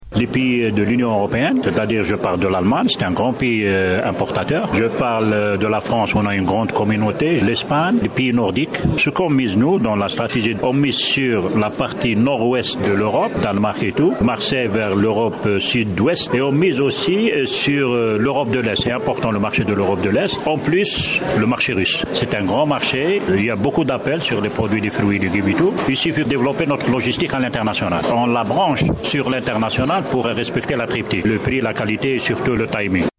Said Djellab, ministre du commerce au micro de al radio Chaine 3